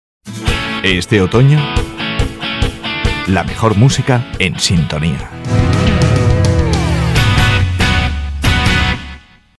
Mesa Soundcraft Altavoces Yamaha Microfono Sennheiser MD 441 U Software Adobe Audition
Sprechprobe: Sonstiges (Muttersprache):
My accent is spanish neutral She has a friendly, expressive, seductive and professional voice.